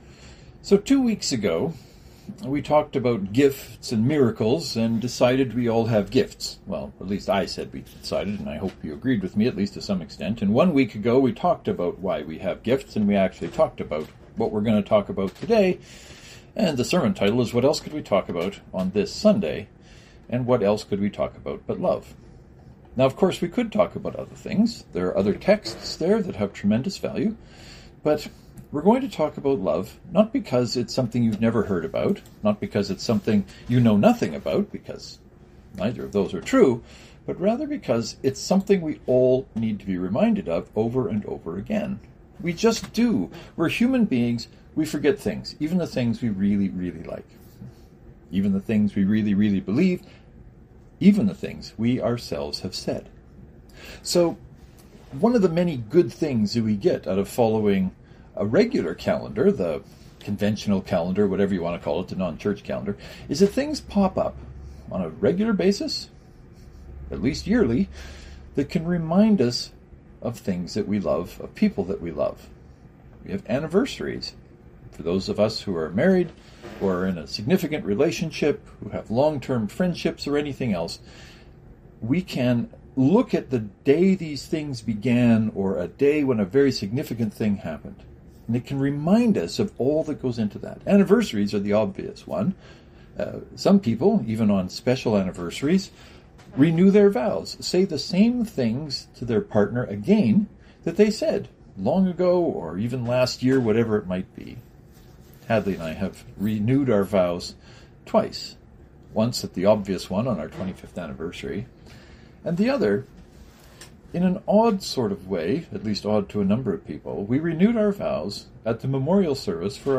This sermon is talking about love.